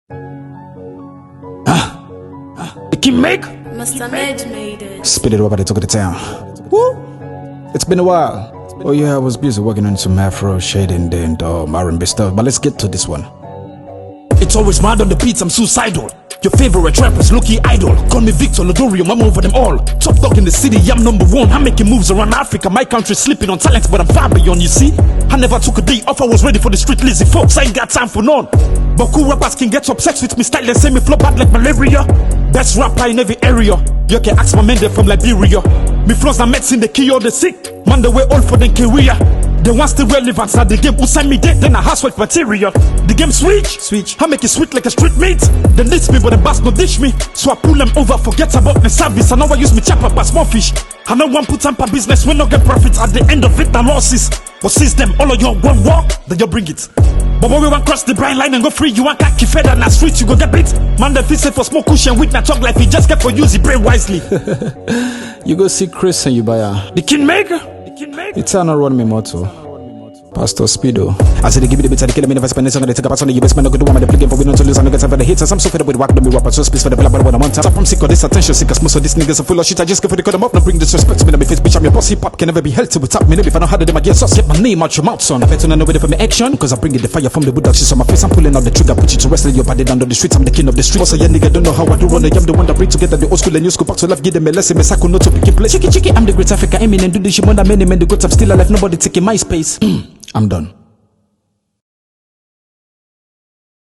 and his name is quickly coming to represent African hip-hop.